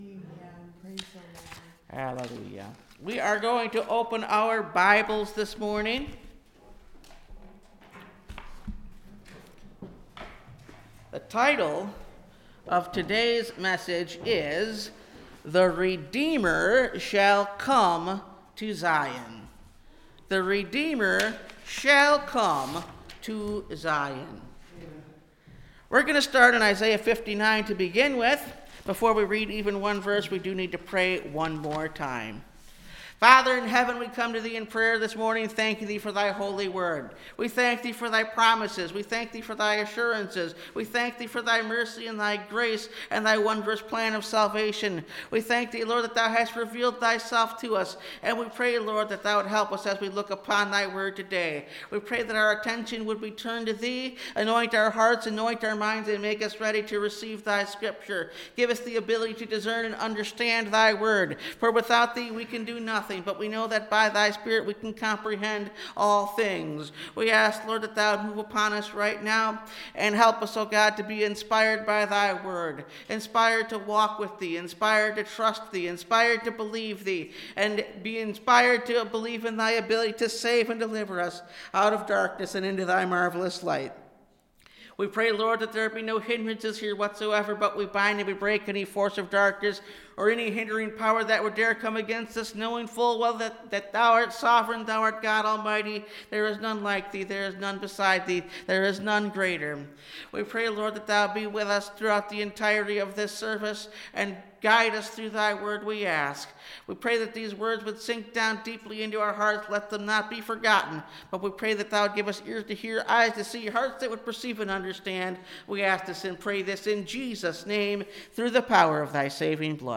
The Redeemer Shall Come to Zion (Message Audio) – Last Trumpet Ministries – Truth Tabernacle – Sermon Library